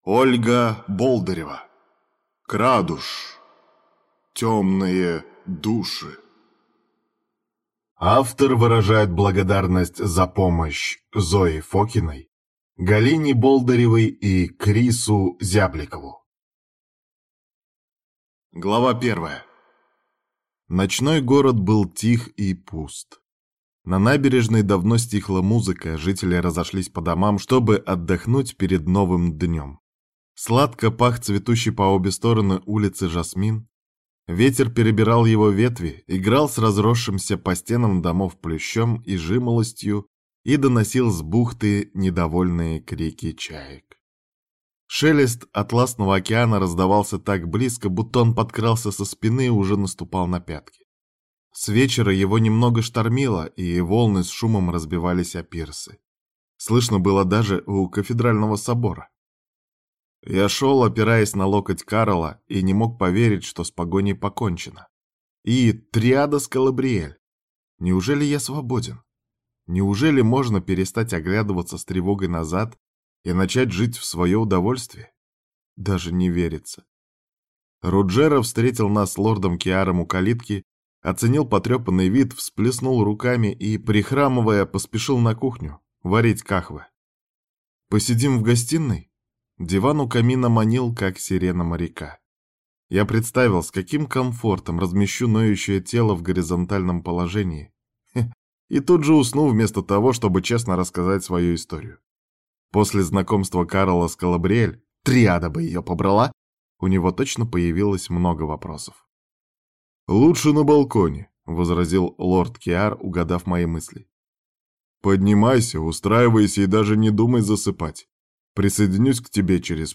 Аудиокнига Крадуш. Тёмные души | Библиотека аудиокниг